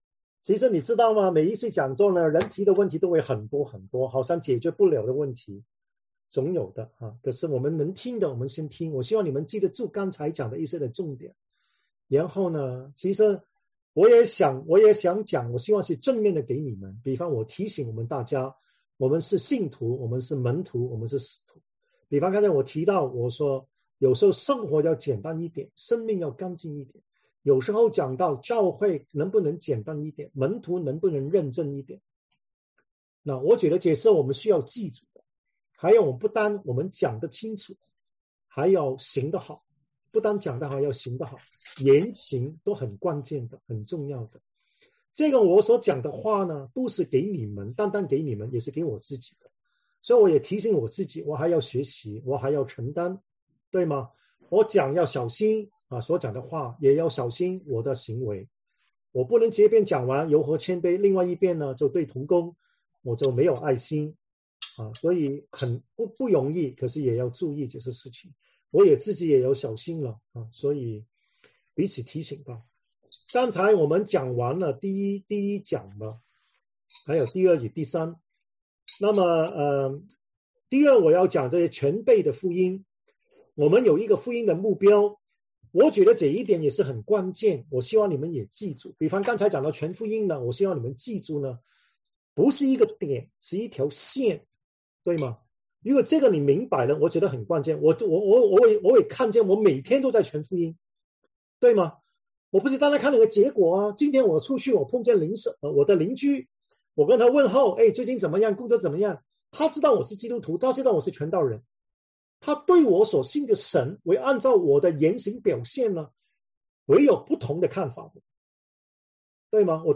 在這講座中將會探討宣教使命的定義、內容和實踐，從《聖經》的內容明白宣教，按《聖經》的教導實行宣教，以致教會可增長，信徒可成長。